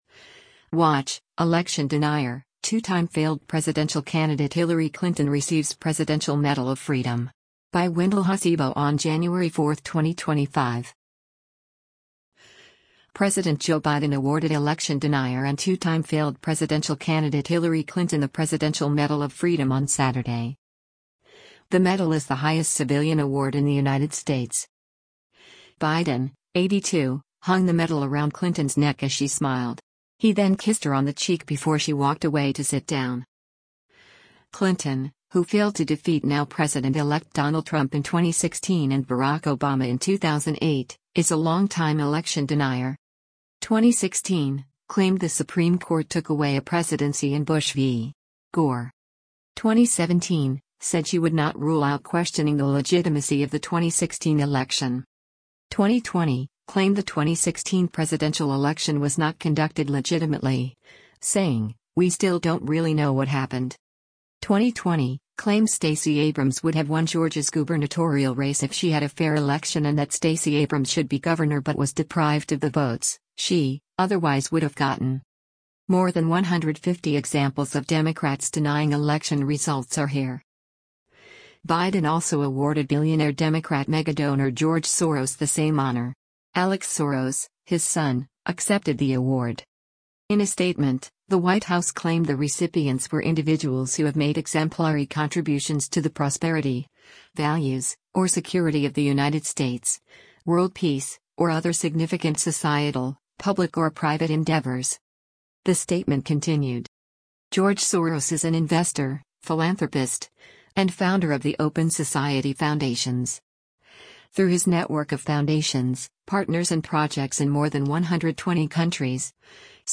President Joe Biden awarded election denier and two-time failed presidential candidate Hillary Clinton the Presidential Medal of Freedom on Saturday.